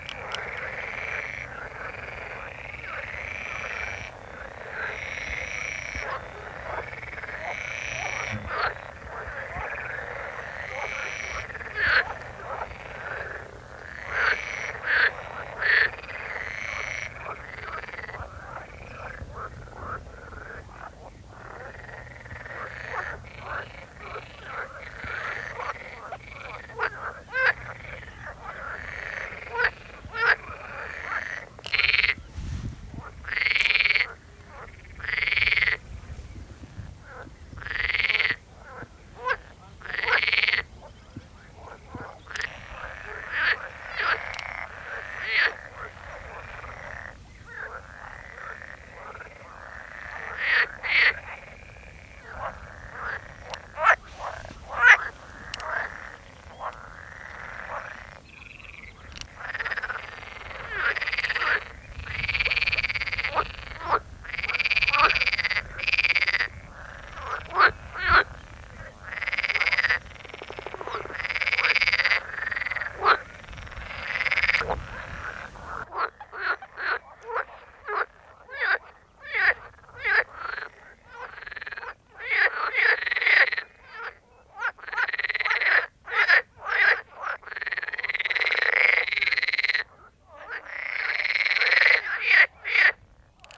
groene kikker onbekend
🔭 Wetenschappelijk: Pelophylax spec.
groene_kikker_roep.mp3